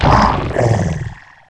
client / bin / pack / Sound / sound / monster / skeleton_god / dead_1.wav
dead_1.wav